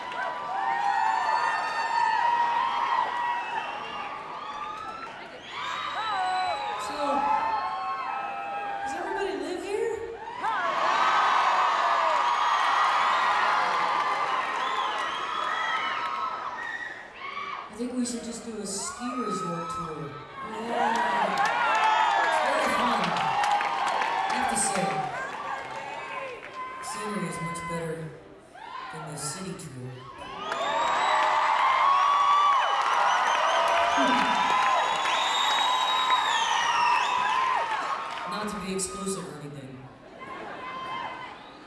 lifeblood: bootlegs: 1994-07-03: stratton mountain - stratton, vermont
(acoustic duo show)
07. talking with the crowd (0:41)